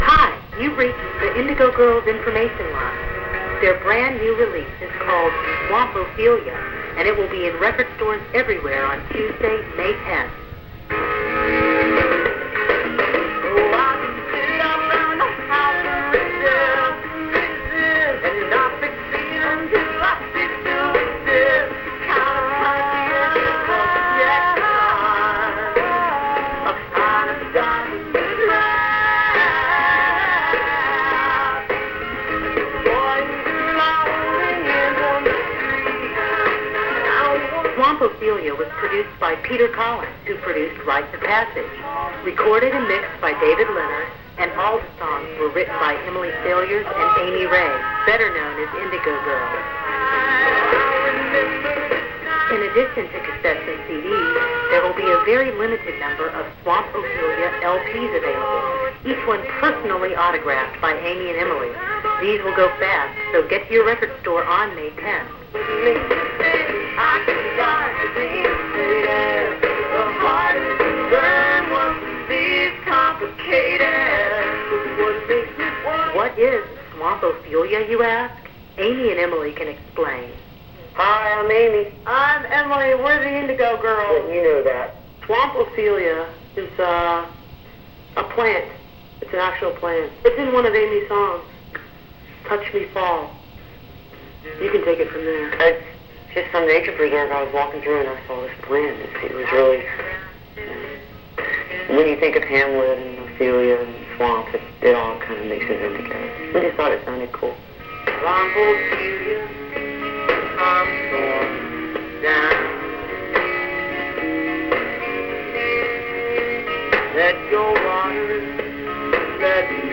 lifeblood: bootlegs: 1994-04: indigo hotline message for "swamp ophelia"
01. hotline message (3:23)